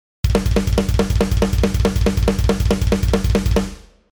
バスドラ2回・スネア1回のパターン（スネア裏打ち）
ブラストビートのサンプル3
• ブラスト（バスドラ2回・スネア1回のパターン）＝グラインド味の強い雰囲気